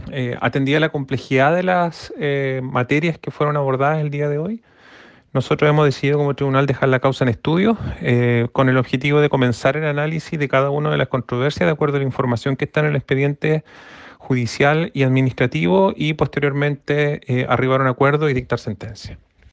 El ministro del Tercer Tribunal, Iván Hunter, confirmó que la causa quedó en estudio.